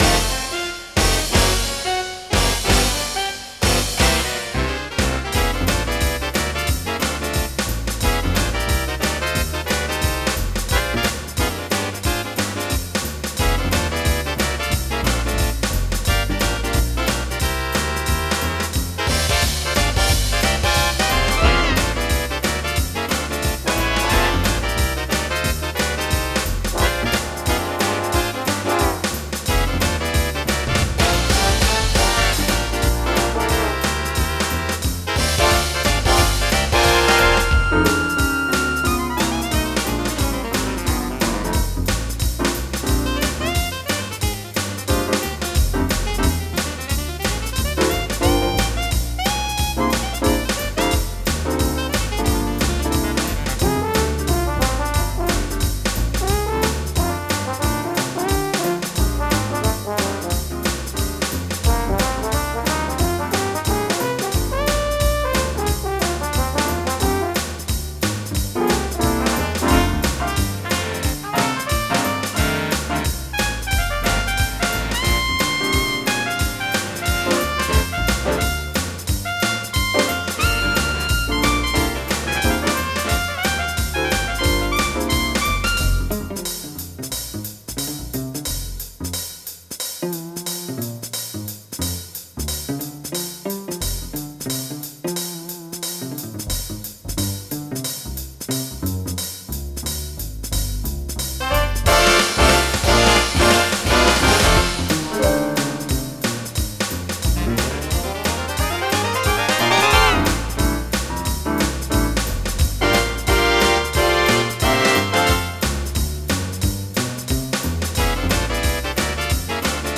MIDI Music File
Type General MIDI